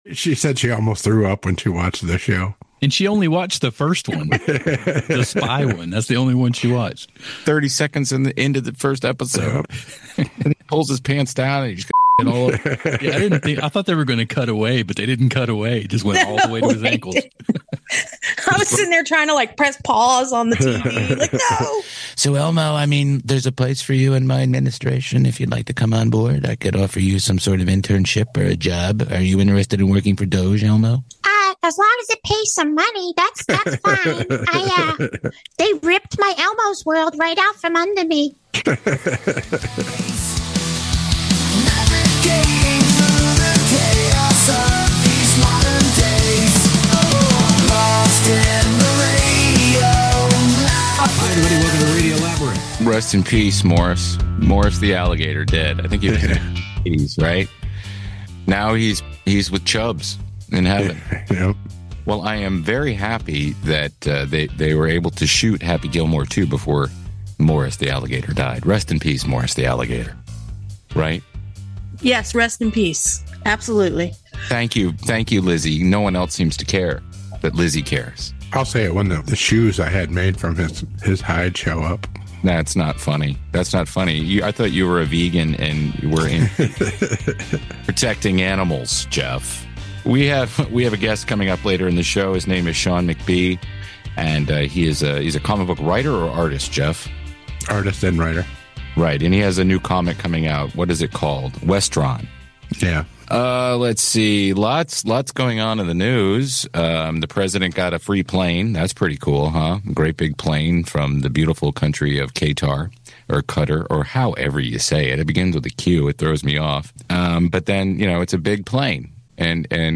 We’re back in the Labyrinth this week with a fresh batch of pop culture chaos and comedic commentary!